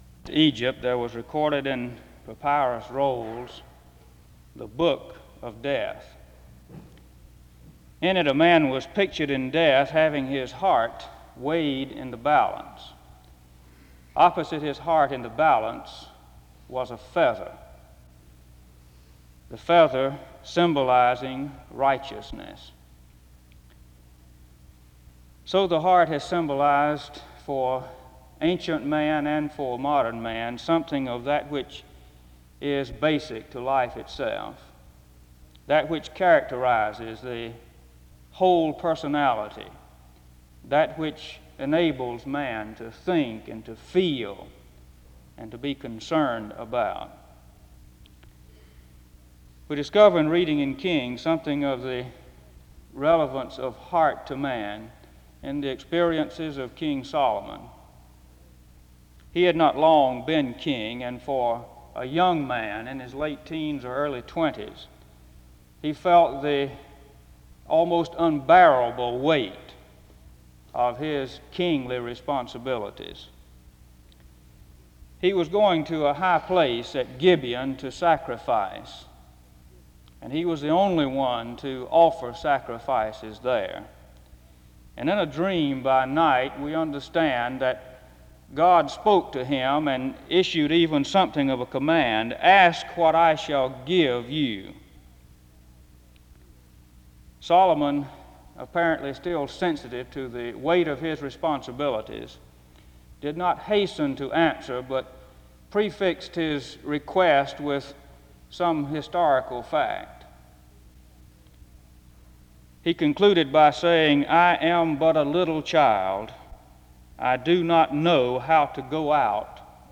[This is only a partial recording of the service.]